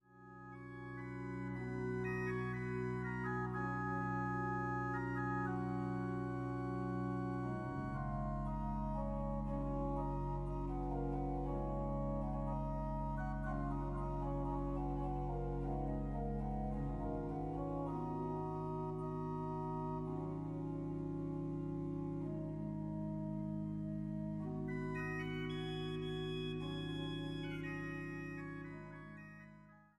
Orgelimprovisationen im Jazzgewand